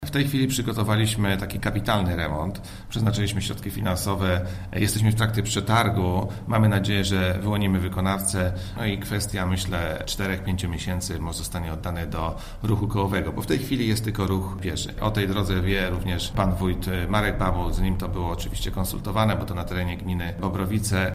– Ponieważ konstrukcja przeprawy jest drewniana, to często ulega uszkodzeniu. Wiemy o tym i po raz kolejny przeprawę będziemy remontować – informuje Mirosław Glaz, starosta krośnieński: